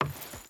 Wood Chain Walk 3.ogg